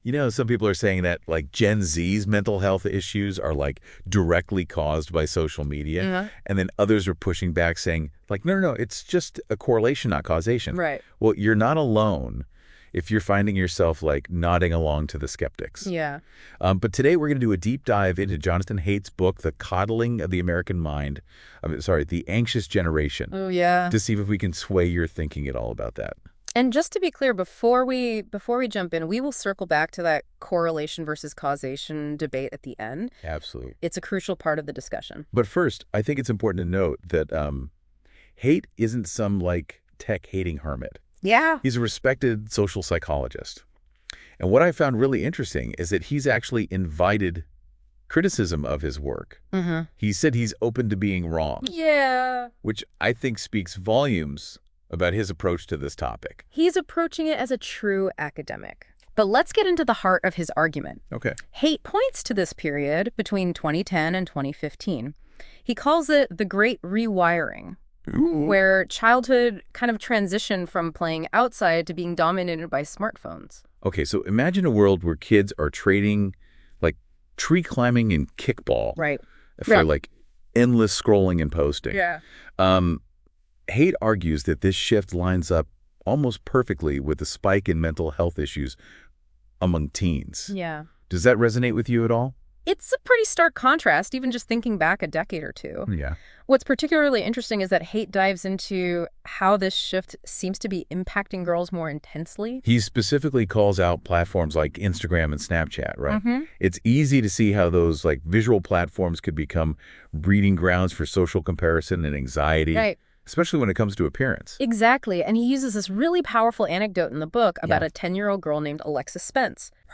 BTW - This is a podcast in an interview style (much more dynamic) and much more engaging! read more